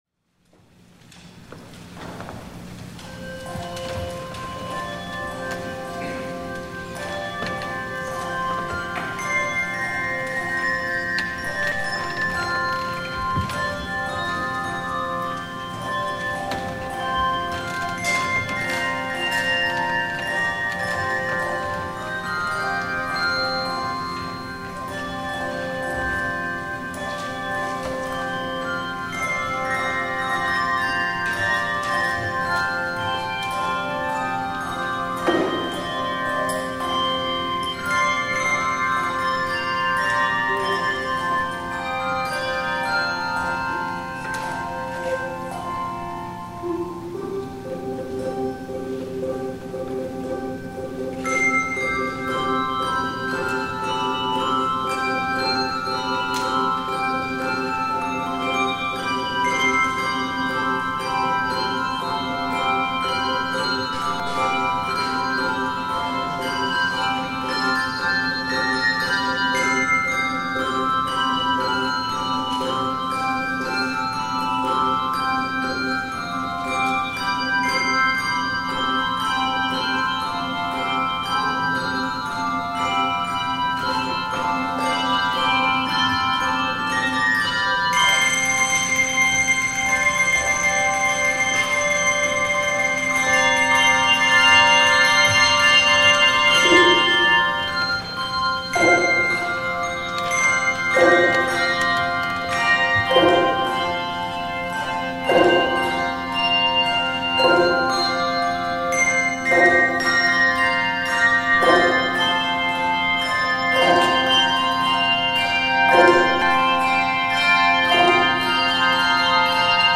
THE OFFERTORY
Handbell Choir